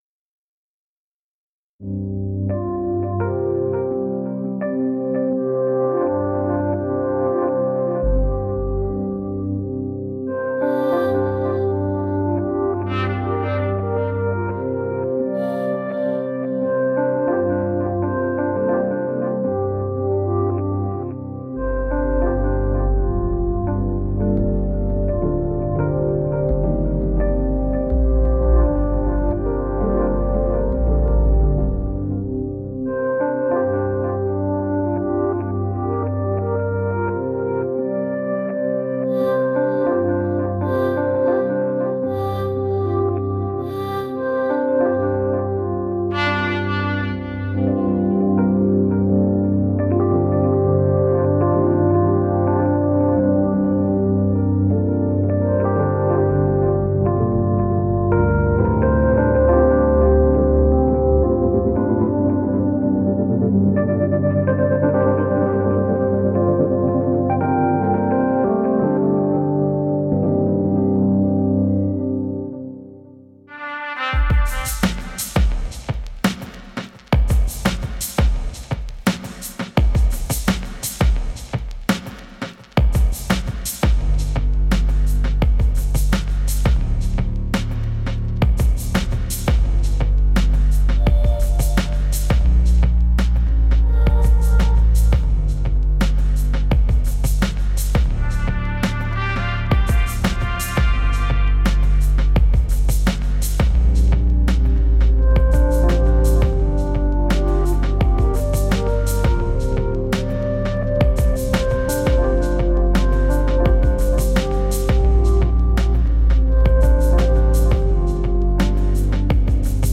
Bei den beiden anderen Beispielen kam jeweils nur ein Loop zum Einsatz, um die Variationsmöglichkeiten aufzuzeigen.